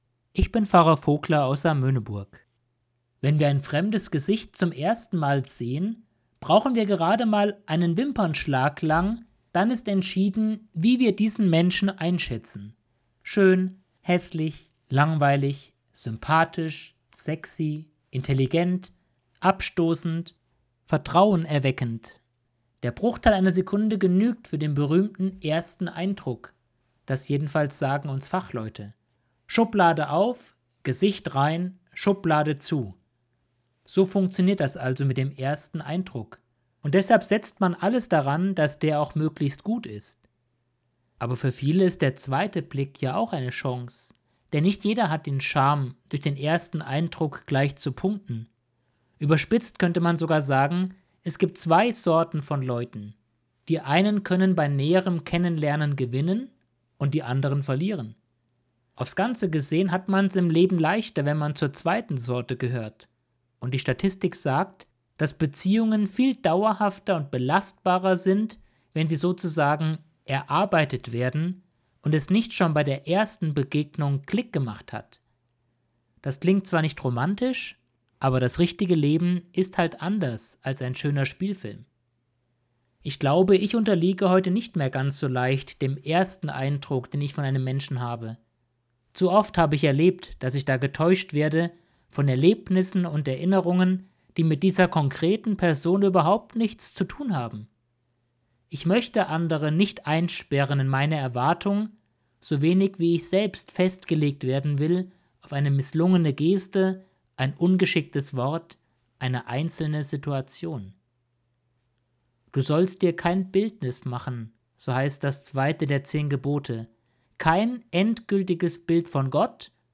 Predigt vom 19. September 2021